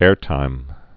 (ârtīm)